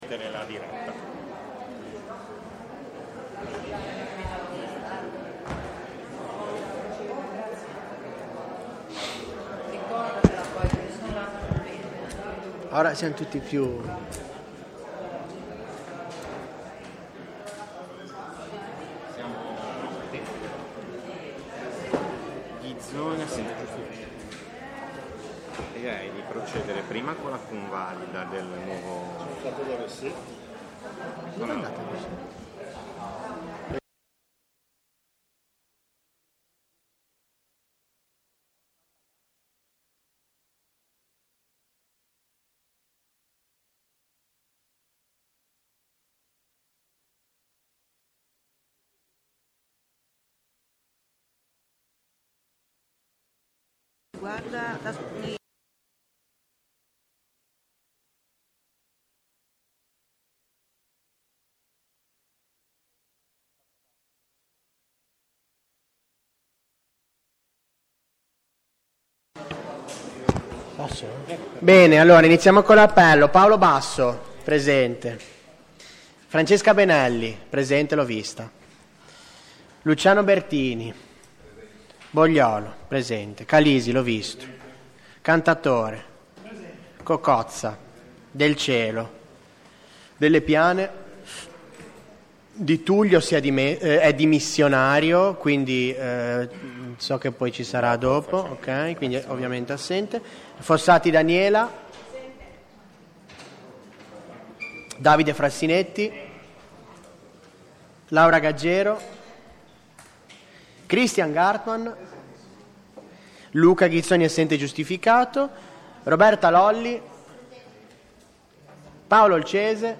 Seduta di Consiglio del Municipio IX Levante | Comune di Genova
La seduta si tiene nella Sala Consiliare del Municipio Levante in Via Domenico Pinasco 7 Canc.